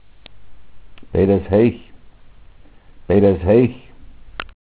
peidas_heich  wohl vorrömischAnhöhe-